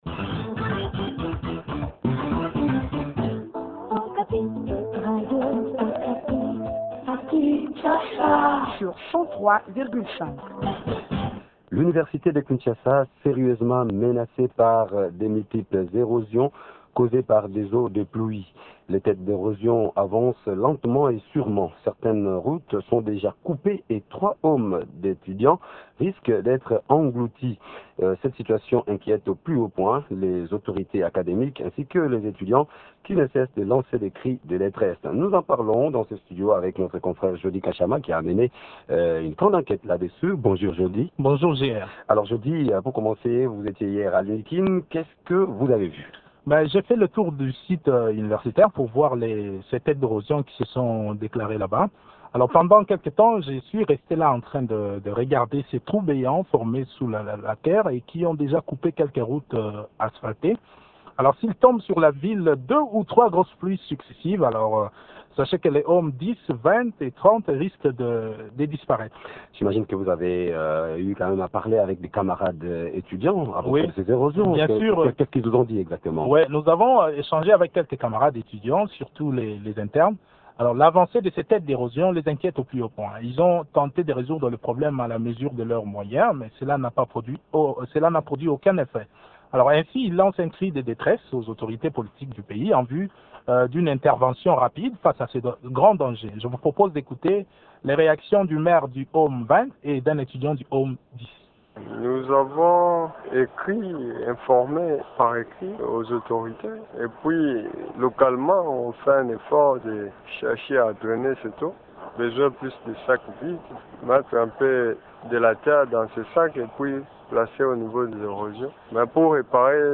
reçoit Benjamin Kasiama, ministre des Travaux publics et Infrastructures.